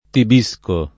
Tibisco [ tib &S ko ] top. m.